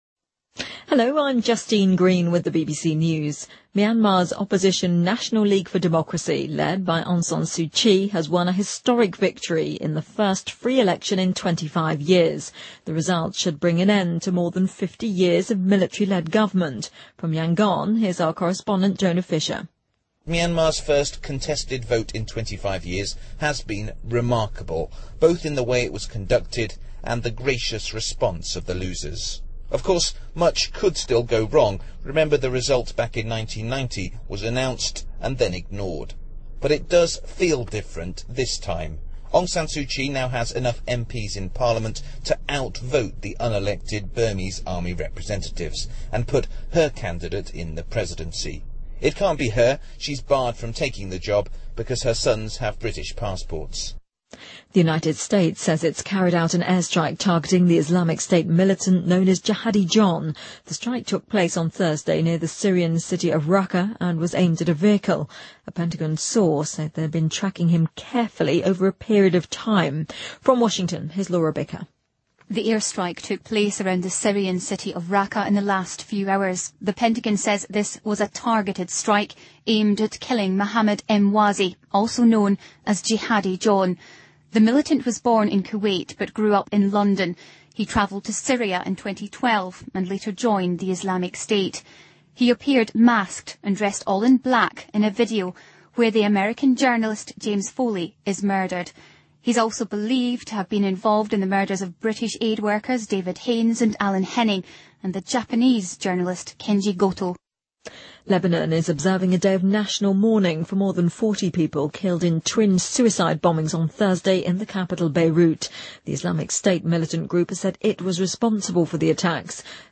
BBC news,格陵兰岛巨型冰川正以前所未有的速度融化
日期:2015-11-16来源:BBC新闻听力 编辑:给力英语BBC频道